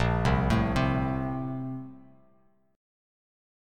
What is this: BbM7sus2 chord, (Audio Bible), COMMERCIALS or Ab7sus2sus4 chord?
BbM7sus2 chord